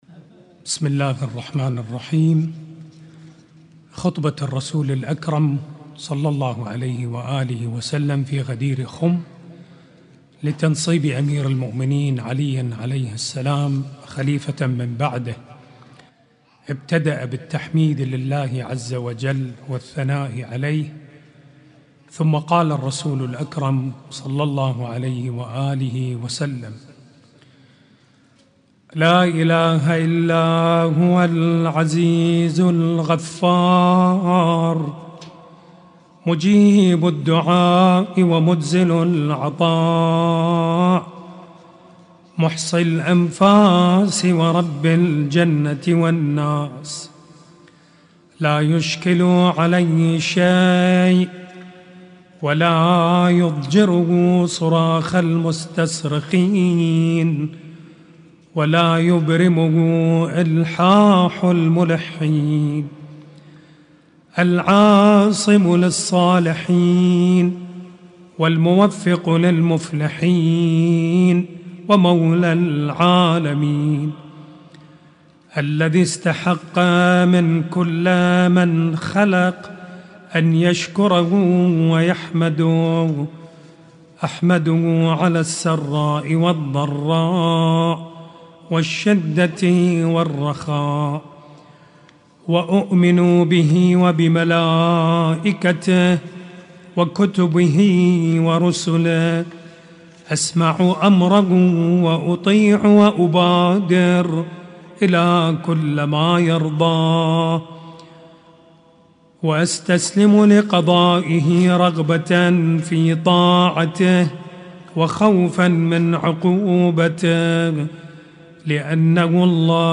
خطبة الرسول الاكرم صلى الله عليه وآله في غدير خم